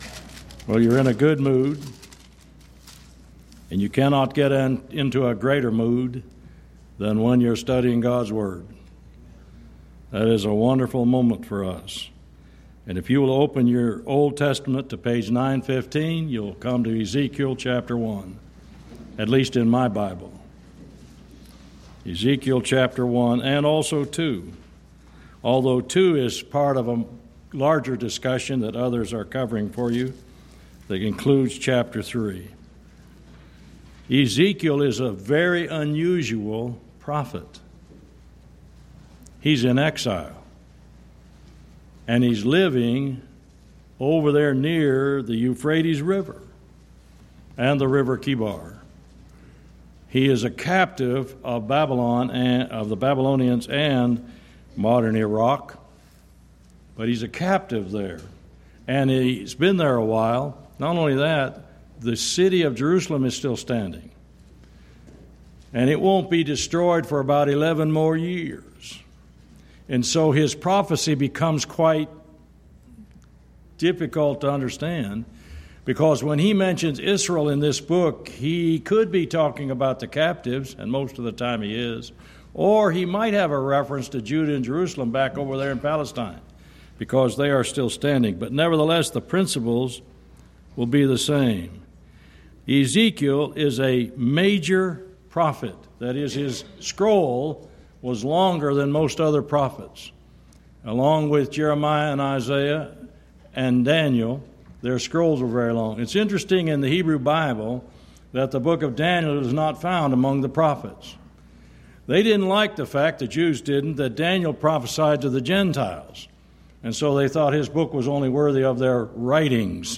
Event: 10th Annual Schertz Lectures
this lecture